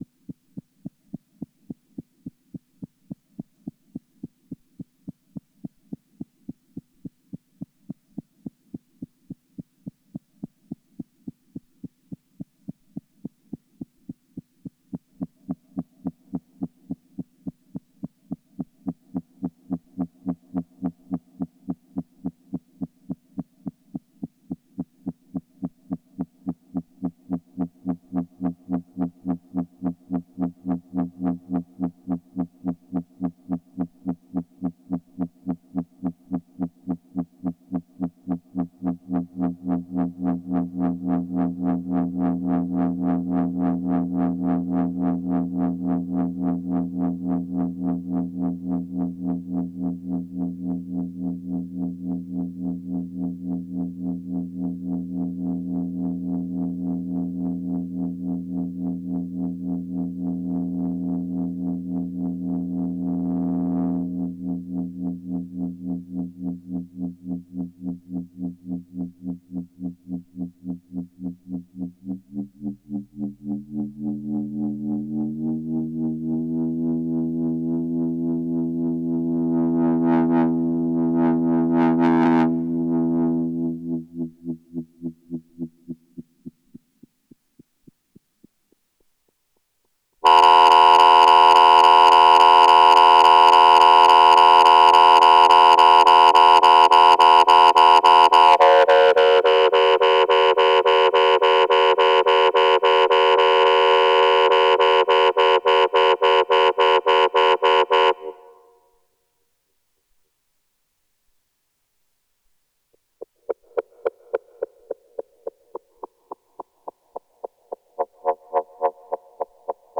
Petit solo de A100 avec 1 seul osc.